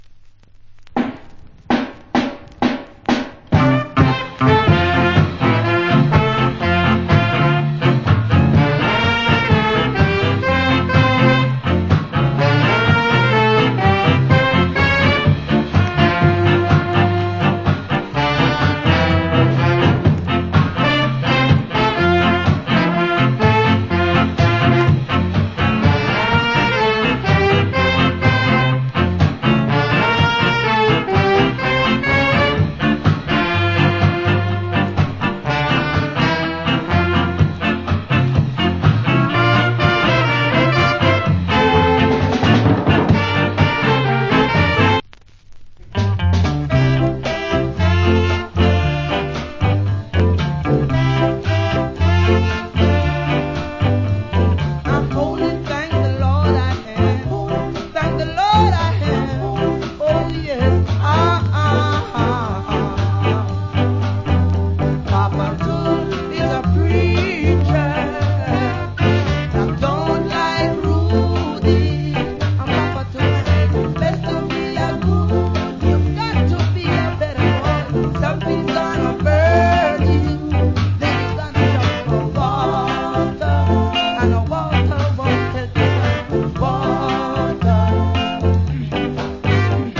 Great Ska Vocal.